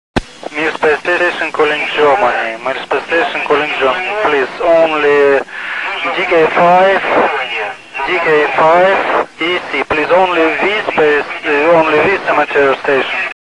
Various recordings from the MIR space station: